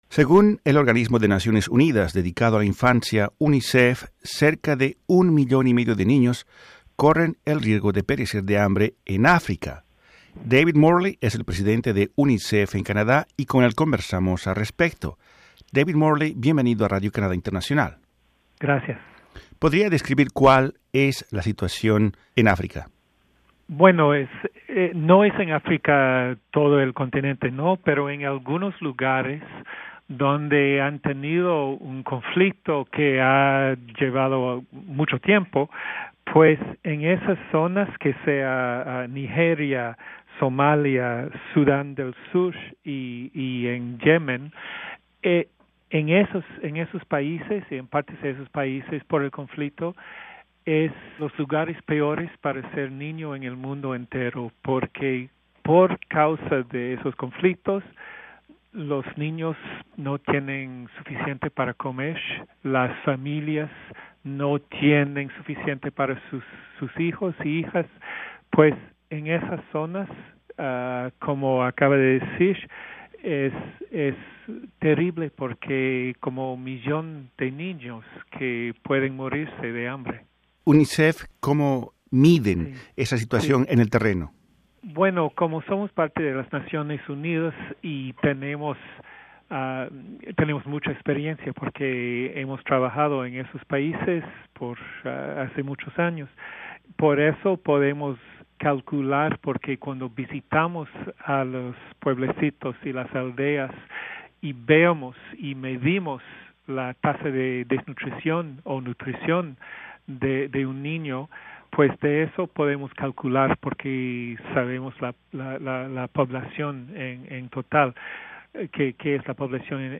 En conversación con Radio Canadá Internacional él explica que una de las causas de esta situación es la guerra.